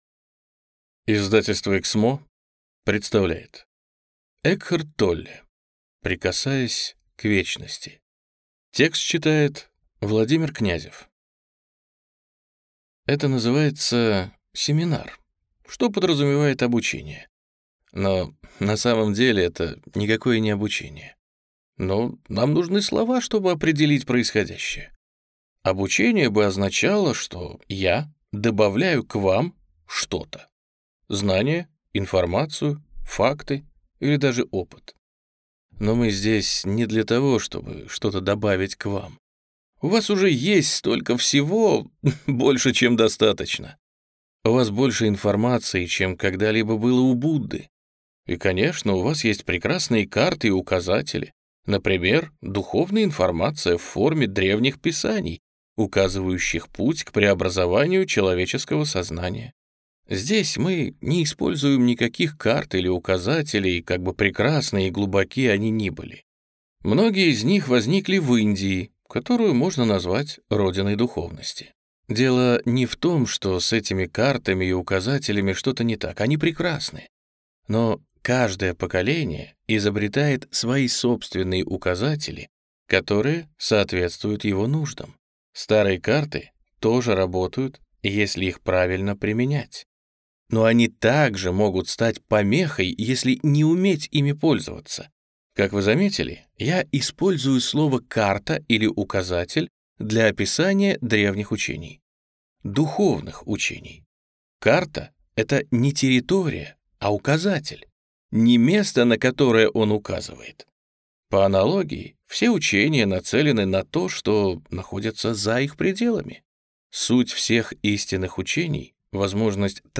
Аудиокнига Прикасаясь к вечности | Библиотека аудиокниг